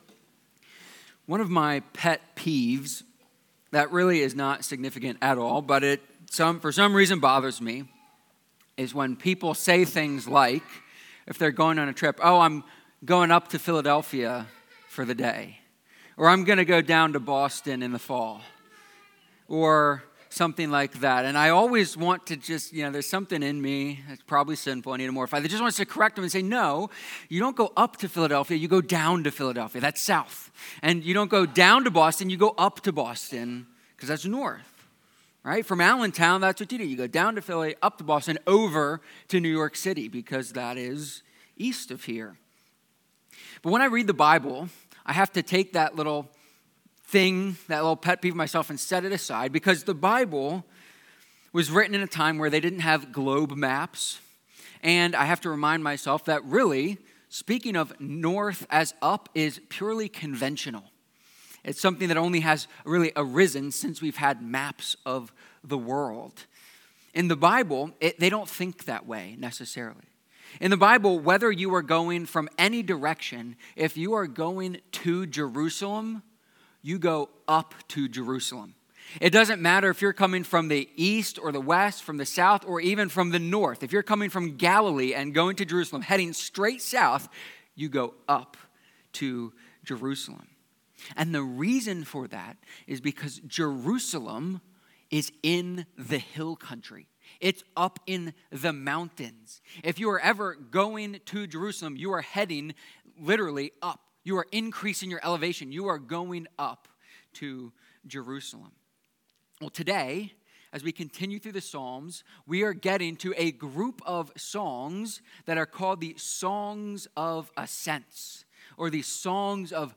Psalm-121-sermon.mp3